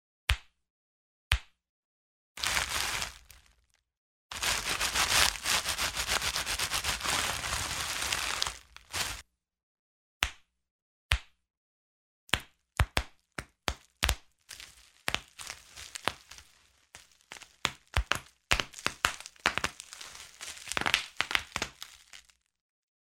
SFX – BUBBLE WRAP
SFX-BUBBLE-WRAP.mp3